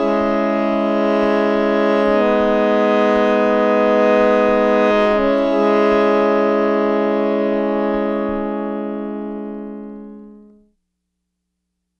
Harmonium Female 05.wav